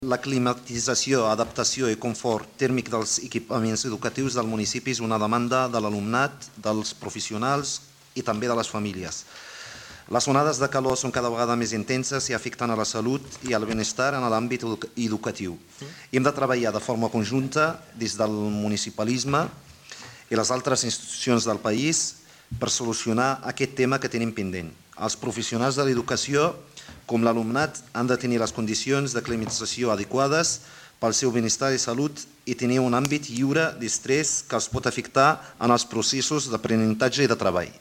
Així es va acordar, per unanimitat, en una moció presentada al Ple Municipal d’aquest dilluns.
Soulimane Messaoudi, portaveu d'ERC